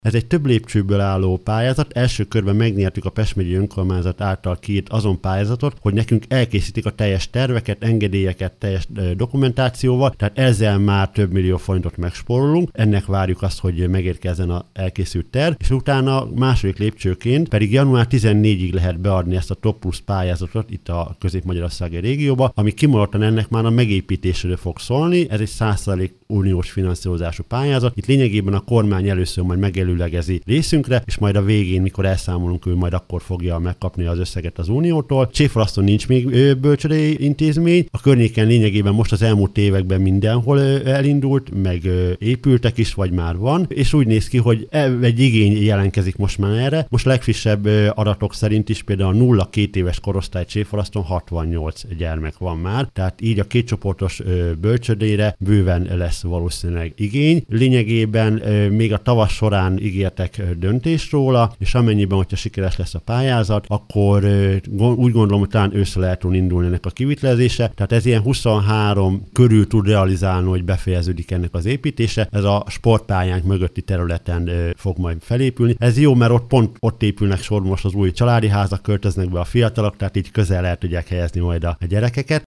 Pulisch József polgármester ismertette a bölcsőde terveinek részleteit.